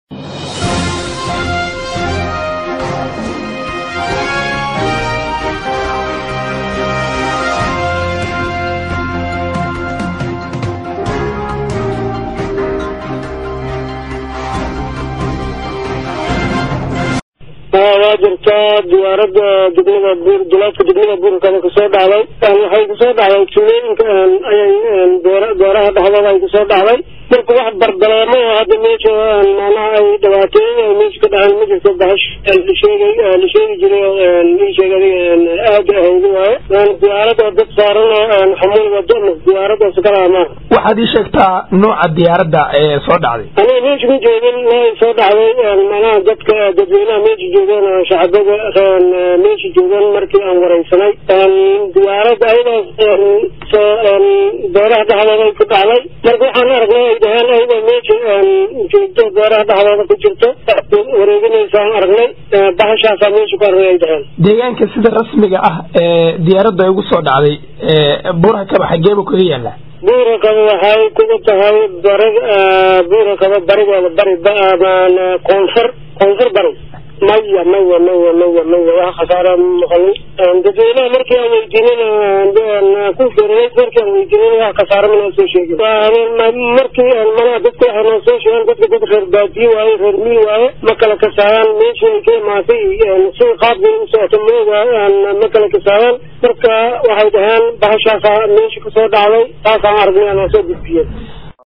Gudoomiyaha degmadan ayaa sheegay in aysan garan karin cida leh diyaradan soo dhacday, balse wuxuu xaqiijiyey in aysan aheyn mid cid saarneyd oo ay hubaan oo kaliya in ay ahayd nooca aan duuliyaha laheyn.
gudoomiyaha-buurhakaba.mp3